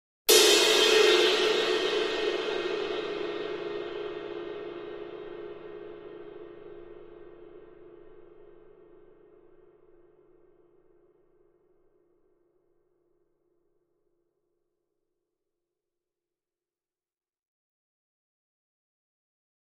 Cymbal, Large, Single Hit, Type 2